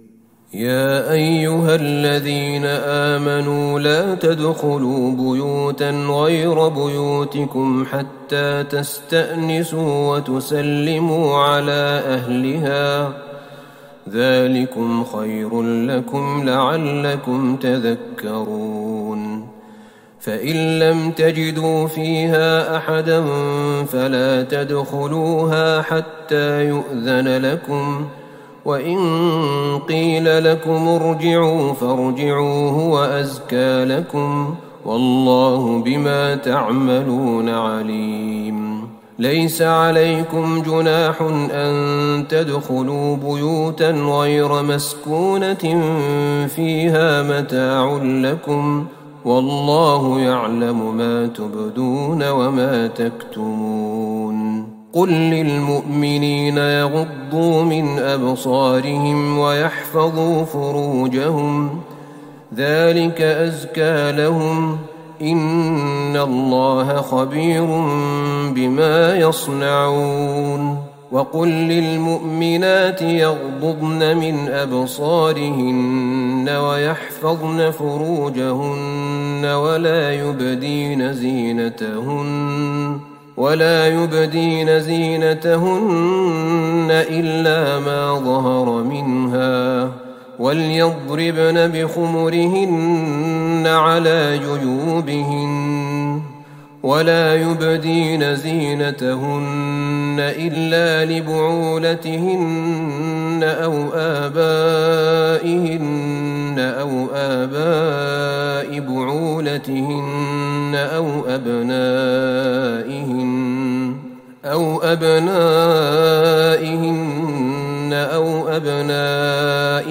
تهجد ٢٢ رمضان ١٤٤١هـ من سورة النور { ٢٧-٦٤ } > تراويح الحرم النبوي عام 1441 🕌 > التراويح - تلاوات الحرمين